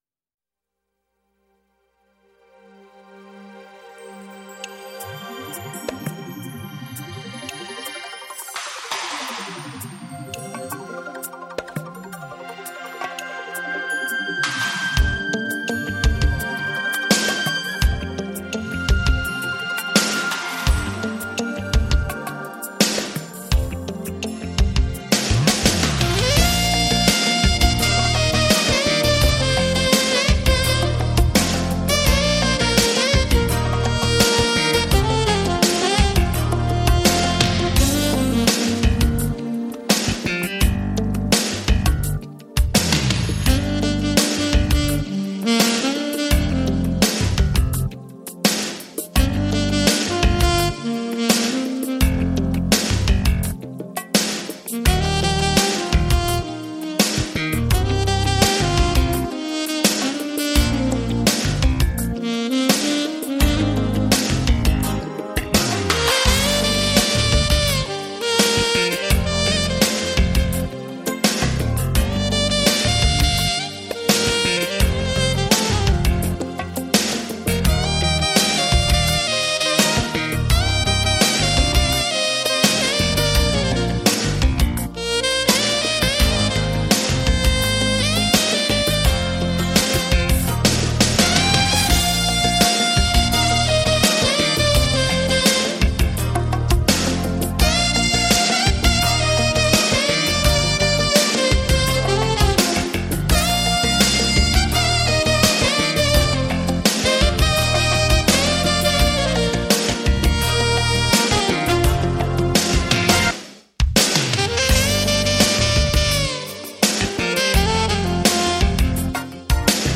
Жанр: Jazz